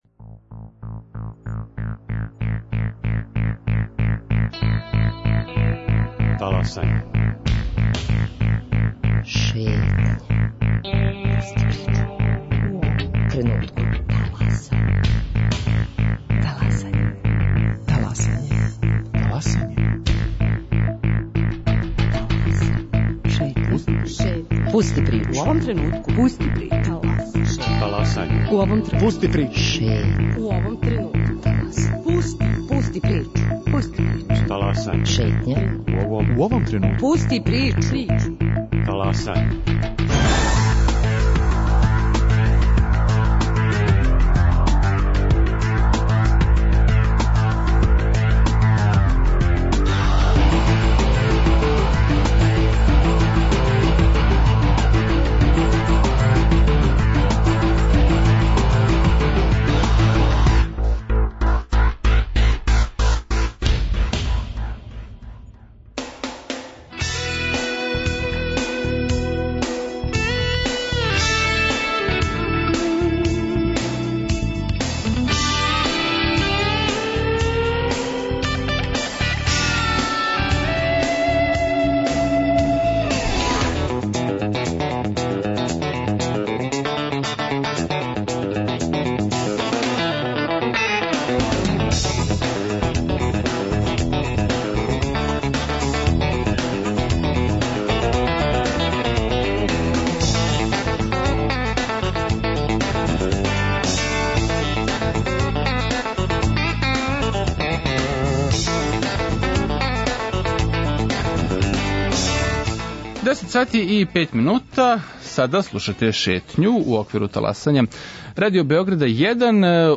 Колаж тема и мноштво саговорника биће обележје и данашње Шетње. Говорићемо о хуманитарној акцији која је привукла велику пажње јавности, чути о могућим дестинацијама за одмор у Србији овог лета и дати идеје за покретање сопственог бизниса.